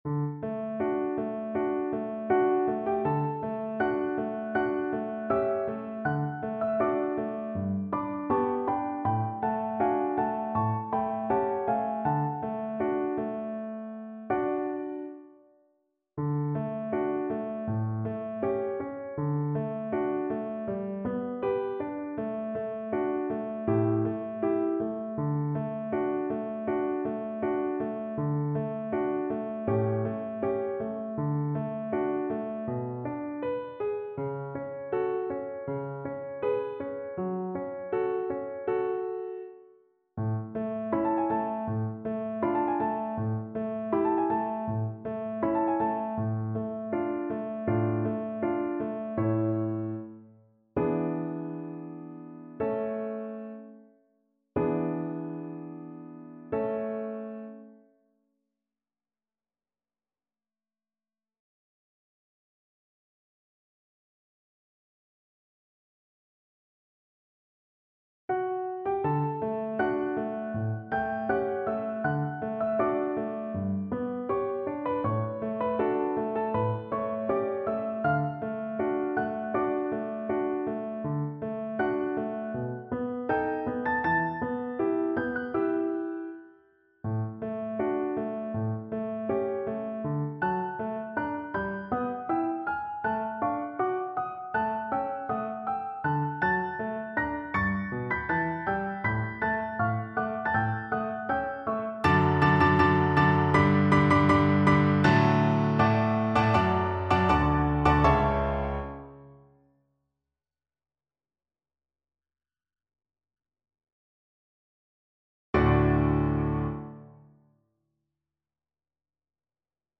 Free Sheet music for Violin
Violin
D major (Sounding Pitch) (View more D major Music for Violin )
Larghetto = c.40
2/4 (View more 2/4 Music)
Classical (View more Classical Violin Music)